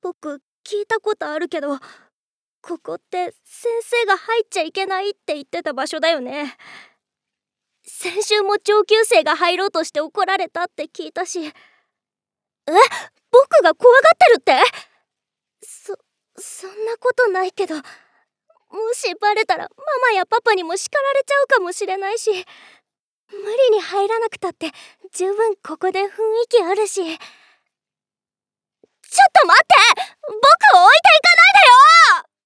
ボイスサンプル3.mp3